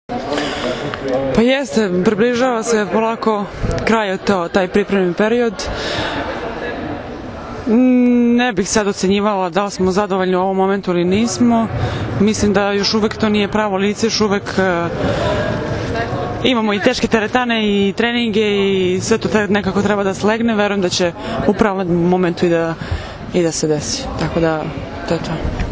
IZJAVA STEFANE VELJKOVIĆ